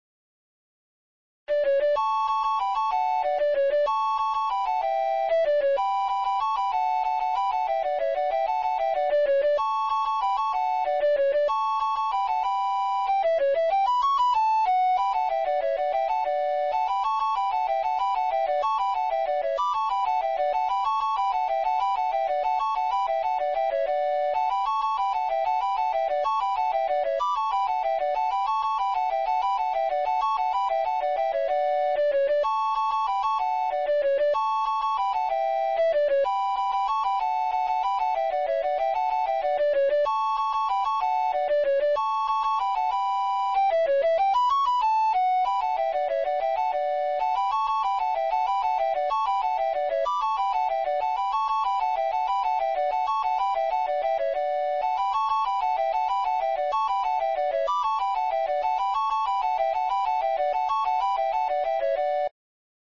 Dúo